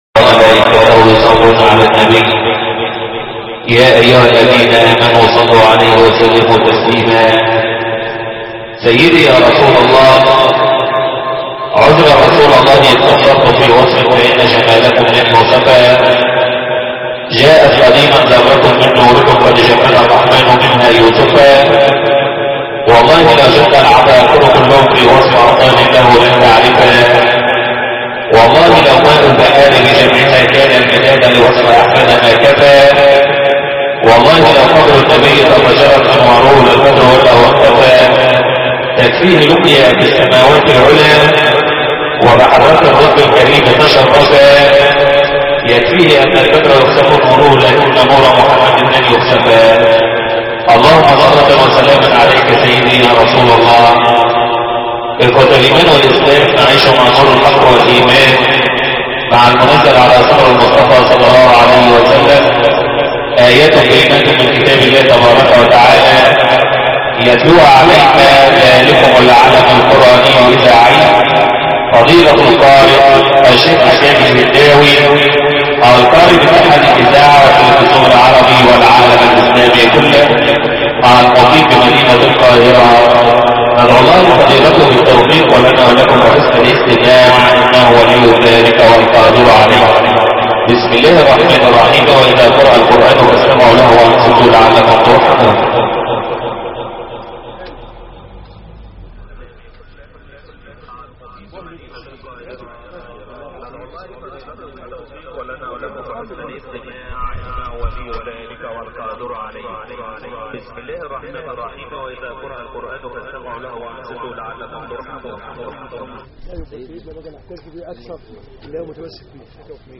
باقة من تلاوات القارئ
القرآن الكريم - الكوثر